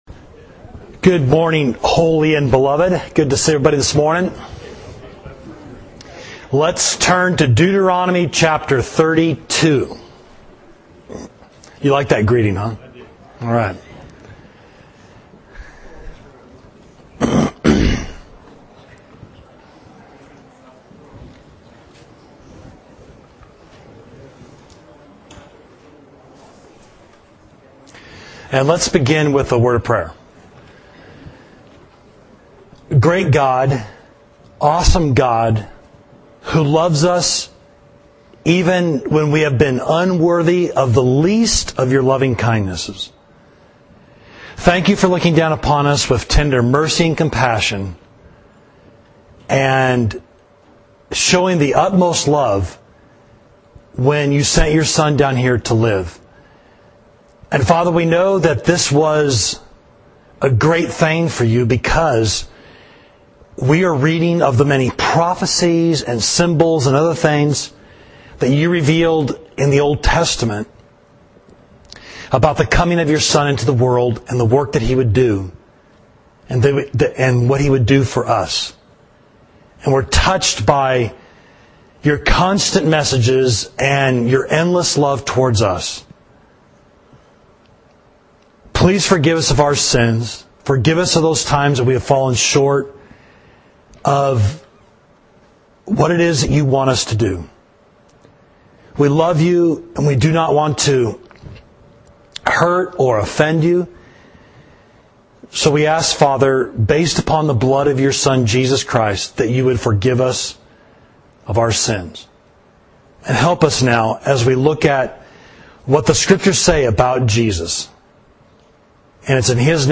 Class: Messianic Prophecies and Their Fulfillment